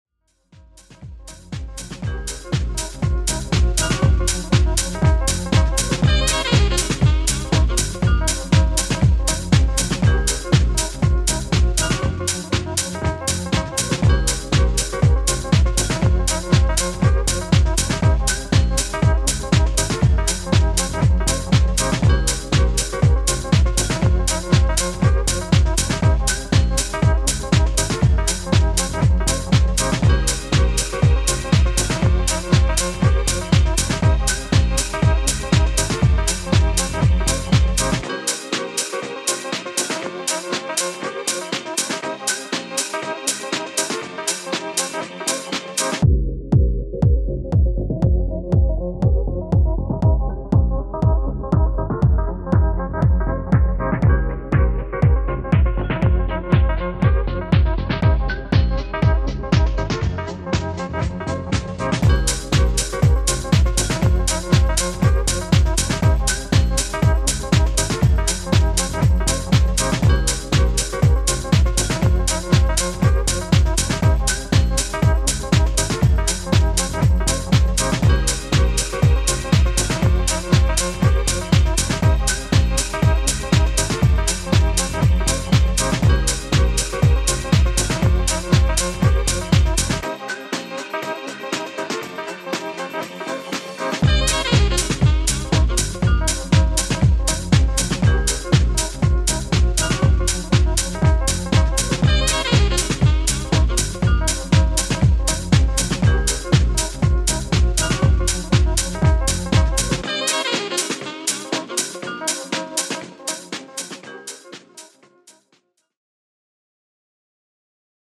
Ass shaking disco for light up, roller-disco dancefloors.
Disco House